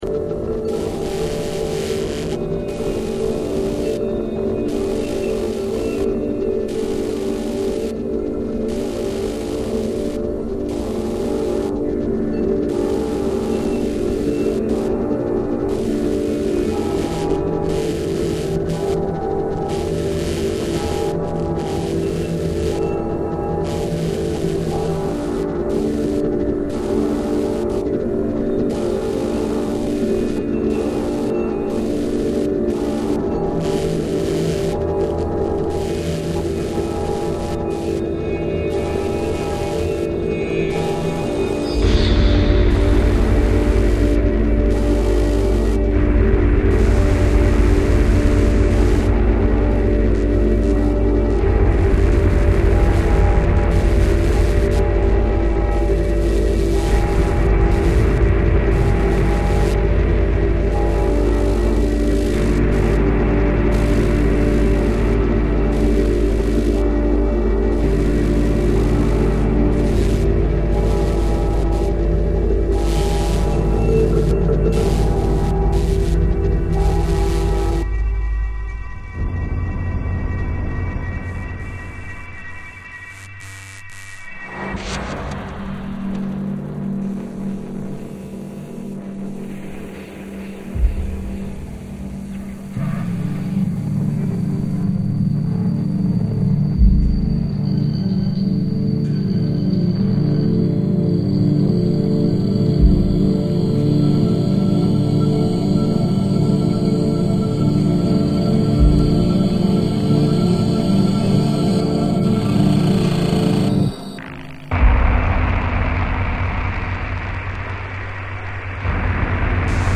electro-acoustic music